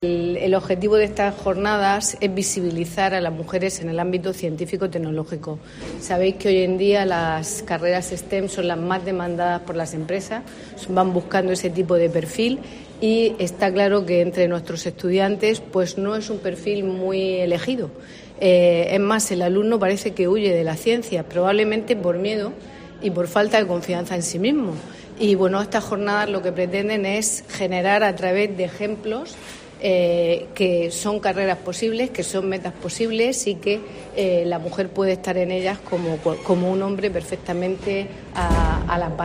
Belén López, concejal de Educación y Atención a la Ciudadanía